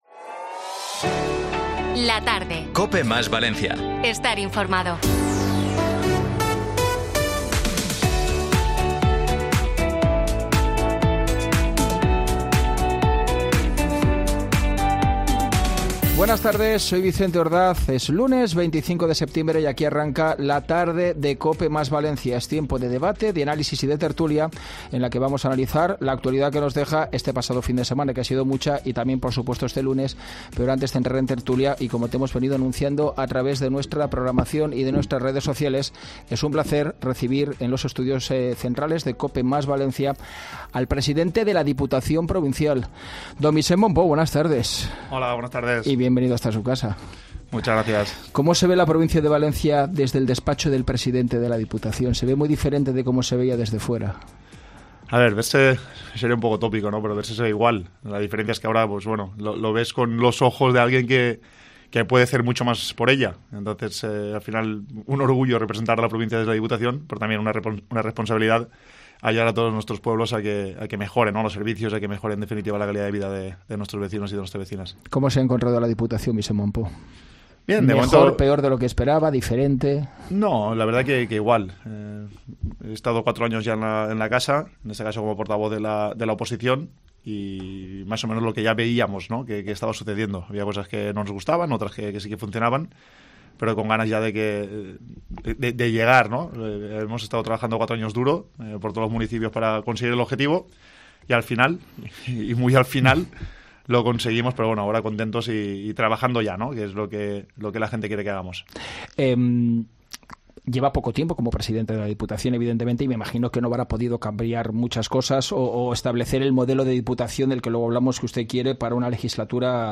Vicent Mompó, presidente de la Diputación de Valencia ha visitado los estudios de COPE para analizar los retos a los que se enfrenta como máximo dirigente de la institución provincial.